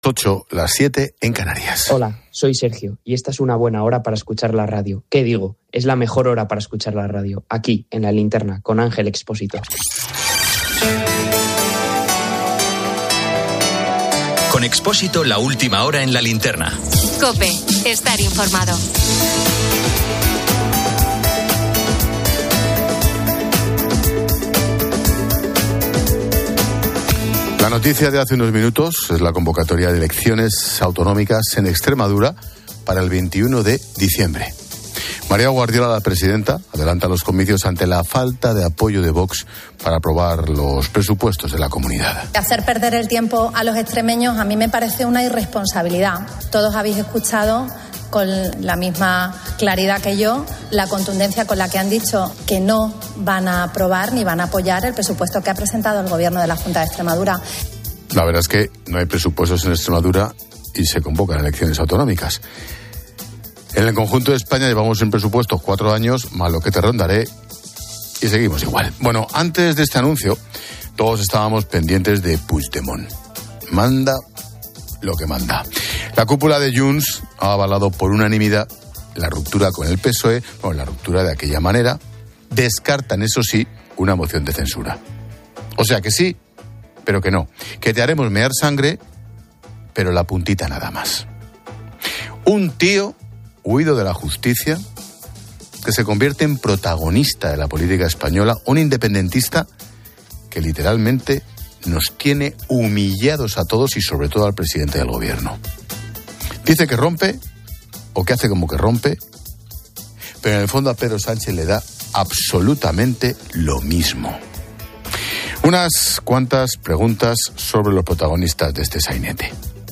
Ángel Expósito presenta "La Linterna" en COPE. Se informa sobre el adelanto electoral en Extremadura, convocado por María Guardiola para el 21 de diciembre por falta de apoyo a los presupuestos.